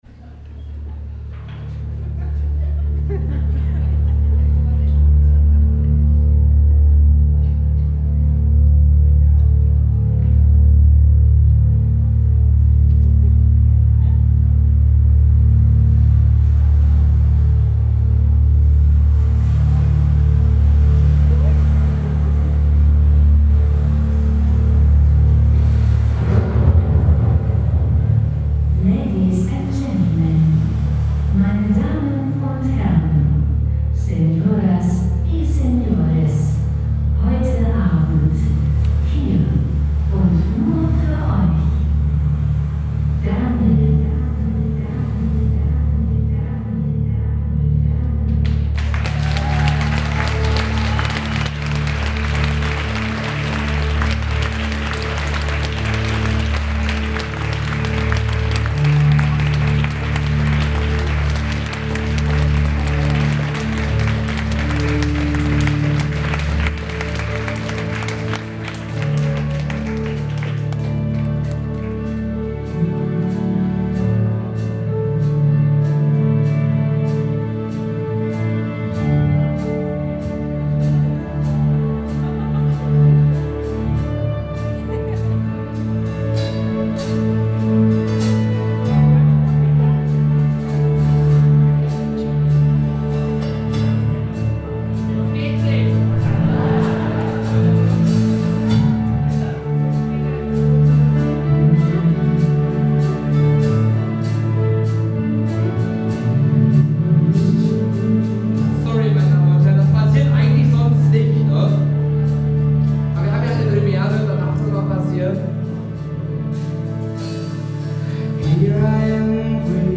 mit hoher Kopfstimme gesungene
zum Konzertmitschnitt